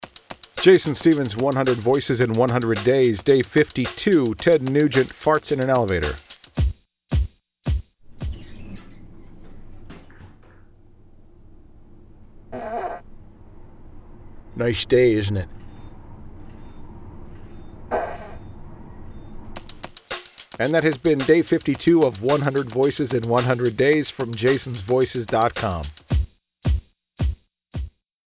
For today’s episode, I’m busting out my Ted Nugent impression.
And from the sound of it, I’m not the only one lacking self-control.
Tags: 100 celebrity voices, Ted Nugent Hillary, Ted Nugent impression, Ted Nugent Trump, voice actor